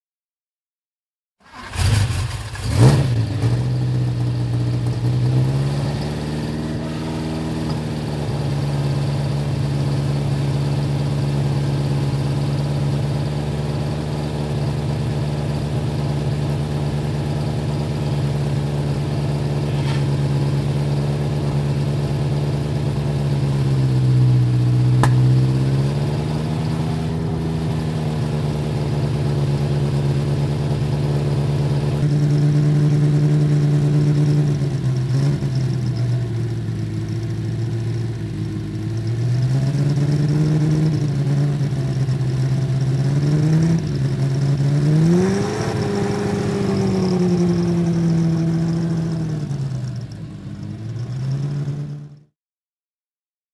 Alfa Romeo 33 Stradale - Anlassen